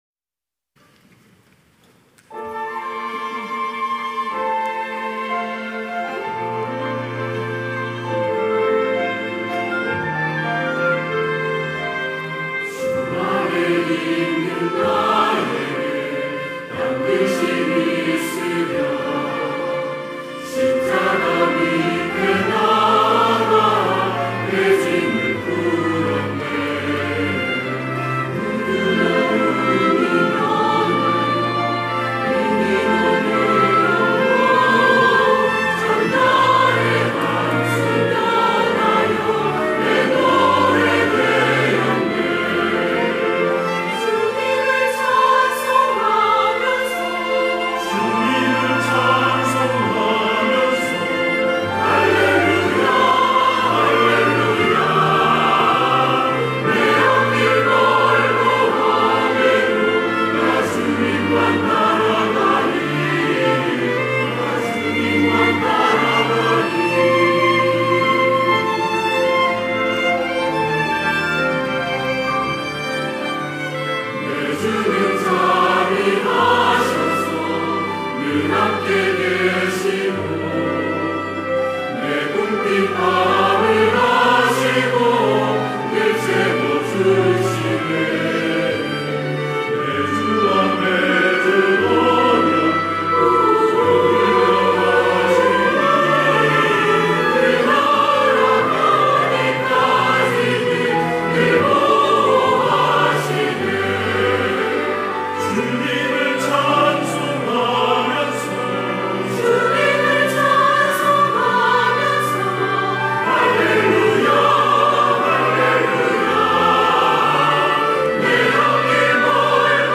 할렐루야(주일2부) - 주 안에 있는 나에게
찬양대